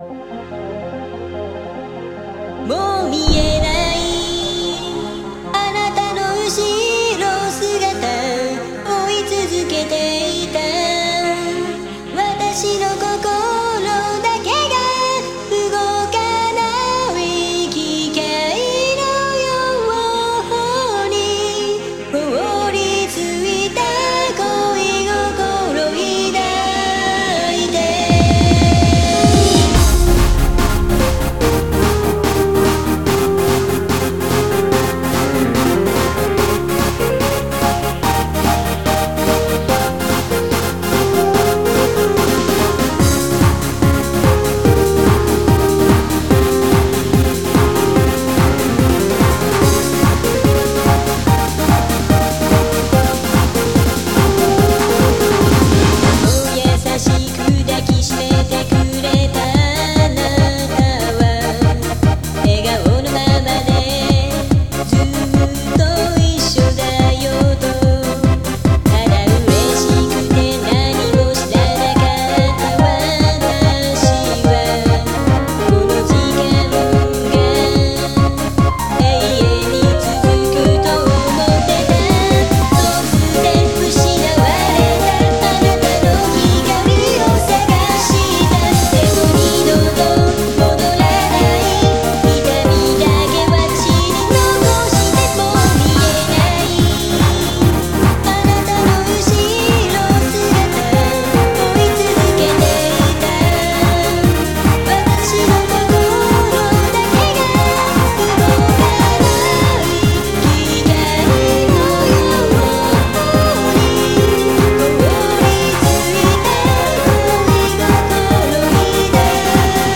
BPM145
Audio QualityPerfect (Low Quality)